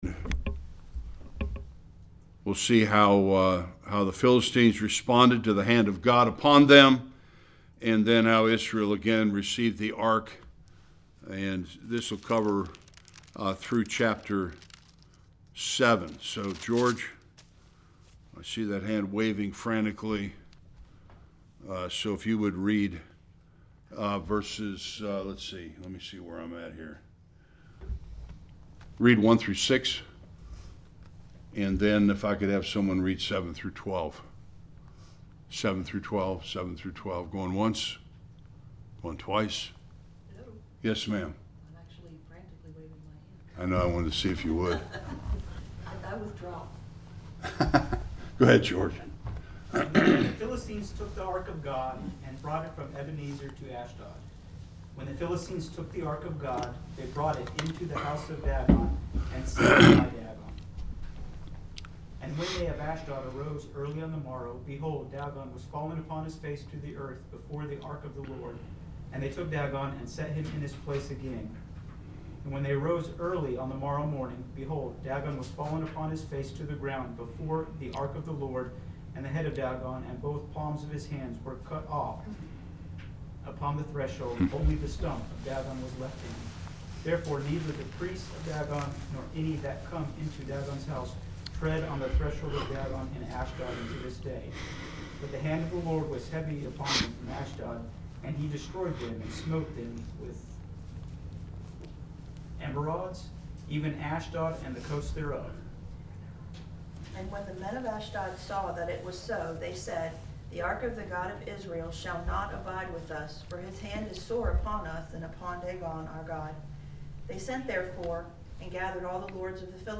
1-11 Service Type: Sunday School The Philistines capture of the Ark of the Covenant and God’s judgment.